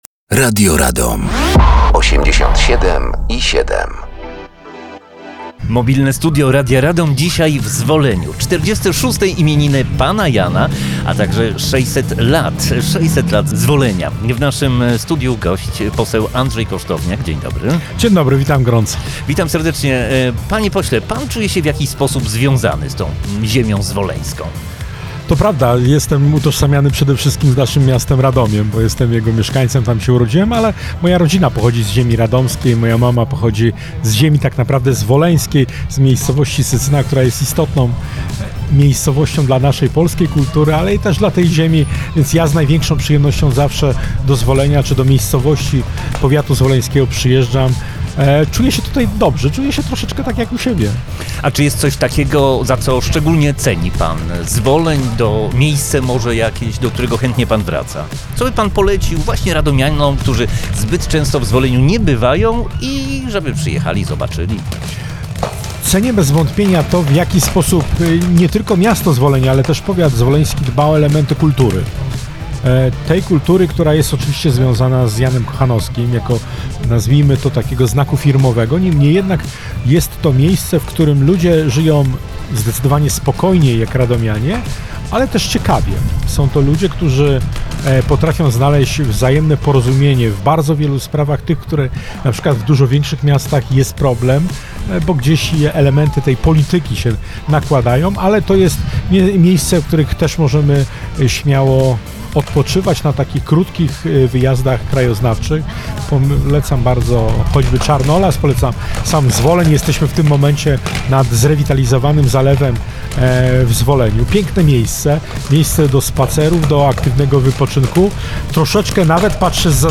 Mobilne Studio Radia Radom gościło w Zwoleniu podczas finałowego koncertu 46 Urodzin Pana Jana.
Gościem był poseł PIS Andrze Kosztowniak